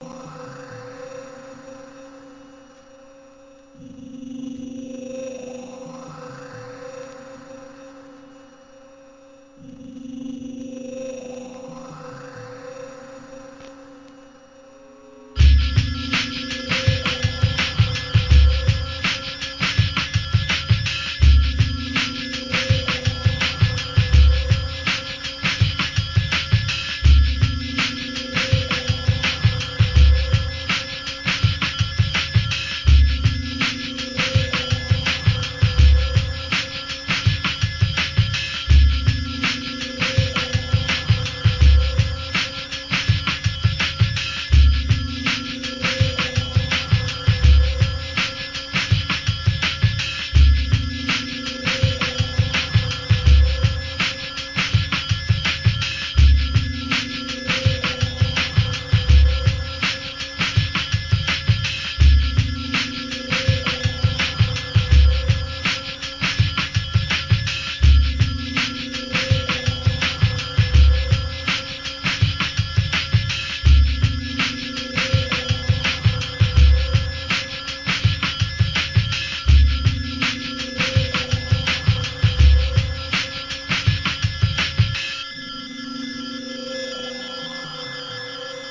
KILLERブレイクビーツ!!